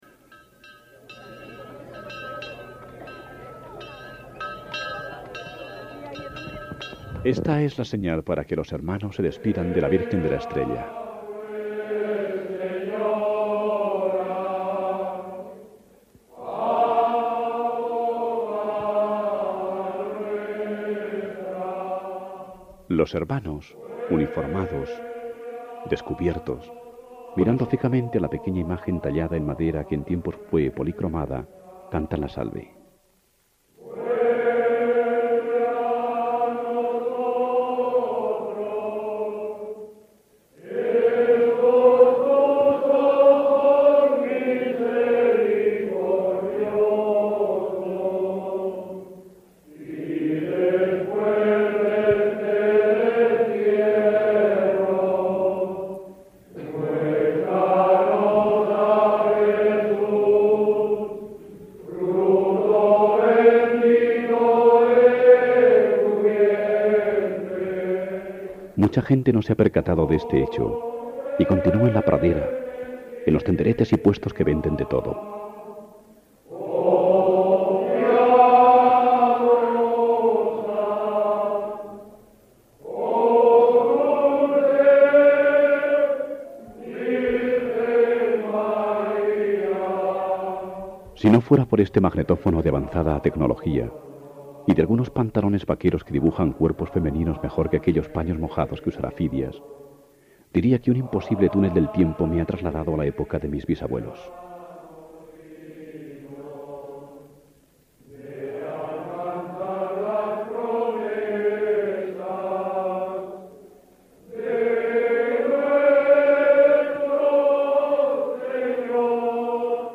En el caso que nos ocupa, “La Caballada” de Atienza, el programa duró, dura, en su montaje final, cincuenta y cinco minutos y, en él, de principio a fin, además de los sonidos de todo el día, se escuchan las voces, las subastas, las opiniones de los que protagonizan la fiesta